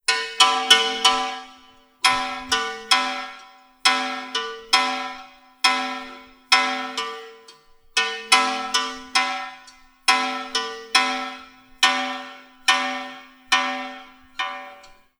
Ramsach_Glocke_v002_kurz.wav